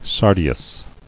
[sar·di·us]